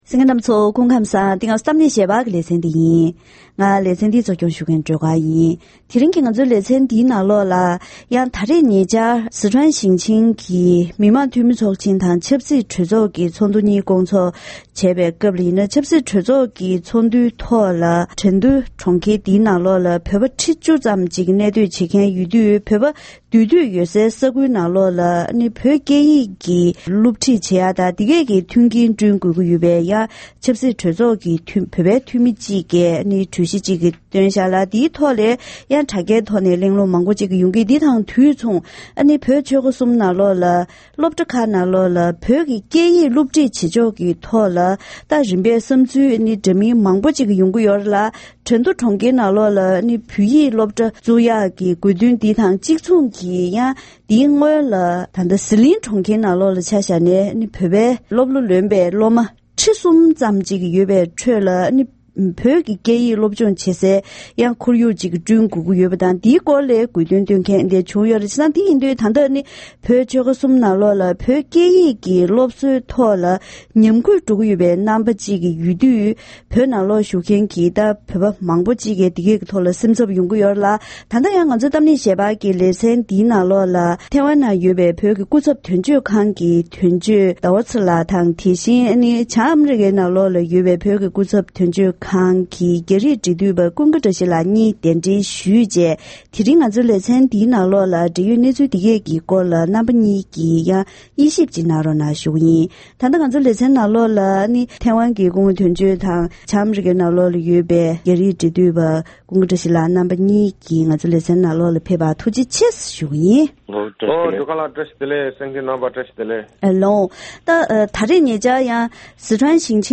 ༄༅། །དེ་རིང་གི་གཏམ་གླེང་ཞལ་པར་ལེ་ཚན་ནང་ཉེ་ཆར་རྒྱ་ནག་གི་ཟི་ཁྲོན་ཞིང་ཆེན་ཆབ་སྲིད་གྲོས་ཚོགས་ཚོགས་སྐབས་བོད་པའི་འཐུས་མི་ཞིག་གིས་གྲན་རྡུ་གྲོང་ཁྱེར་ནང་ཡོད་པའི་བོད་ཕྲུག་ཚོར་བོད་ཡིག་སློབ་སྦྱོང་གི་གོ་སྐབས་སྐྲུན་དགོས་པའི་བསམ་འཆར་བཏོན་ཡོད་པའི་གནད་དོན་ཐོག་ནས་བོད་ཆོལ་ཁ་གསུམ་ནང་བོད་ཡིག་སློབ་ཁྲིད་ཐད་རིམ་པས་དཀའ་ངལ་འཕྲད་བཞིན་ཡོད་པའི་སྐོར་ལ་གློང་མོལ་ཞུས་པ་ཞིག་གསན་རོགས་གནང་།